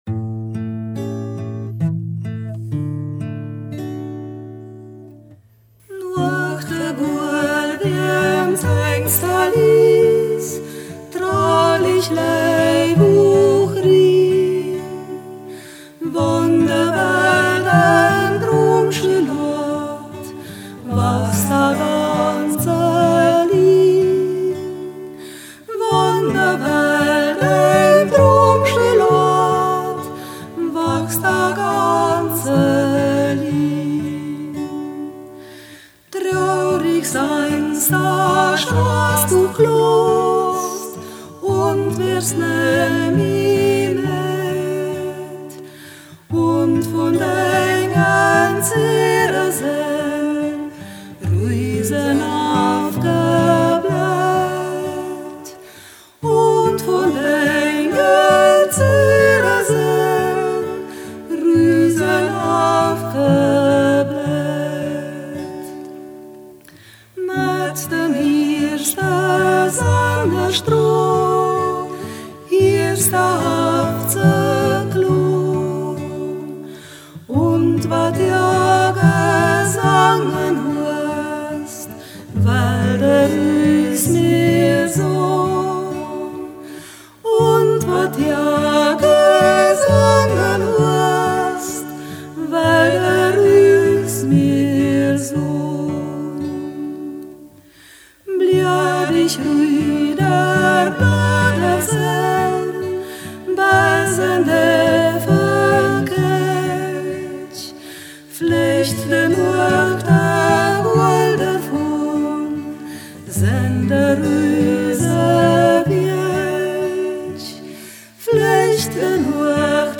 Umgangssächsisch
Ortsmundart: Mediasch